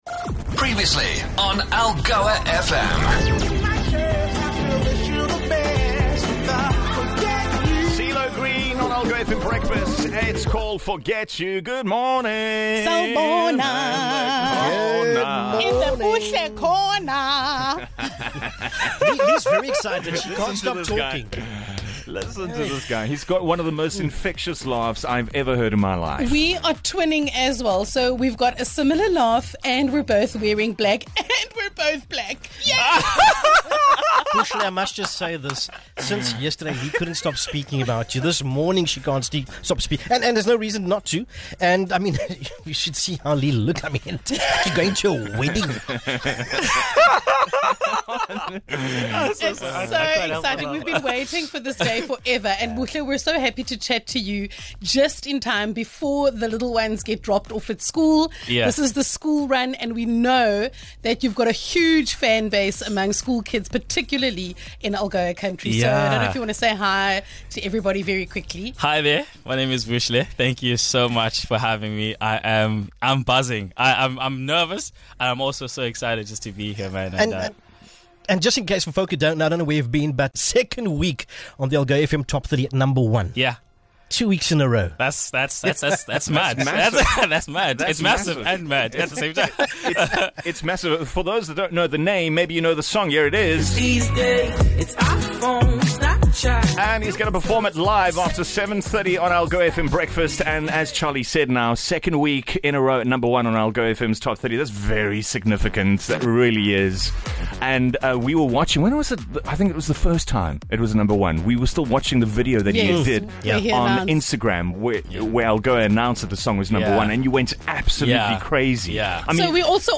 The local lad with oodles of talent joined the team for a catch-up and not one, but two, live performances - if you missed it - here it is - a morning of laughs aplenty as Algoa Country showed their support for this rising star.